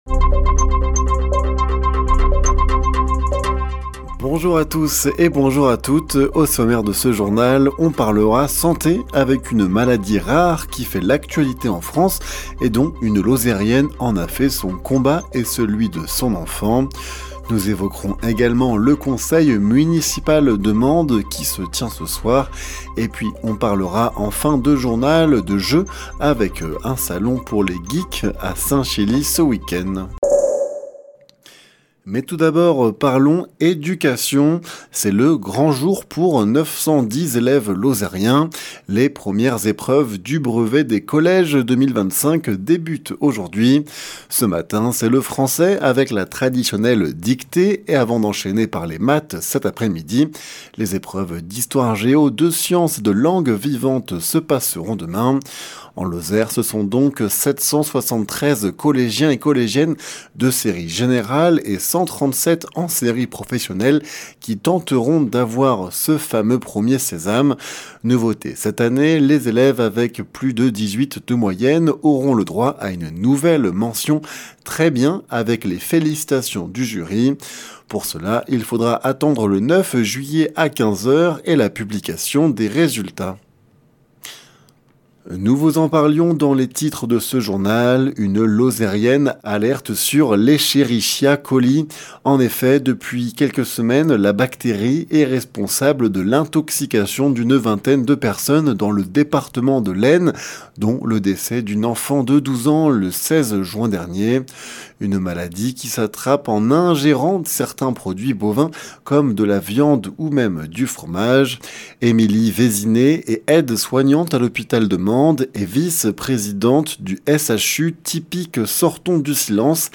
Les informations locales
Le journal sur 48FM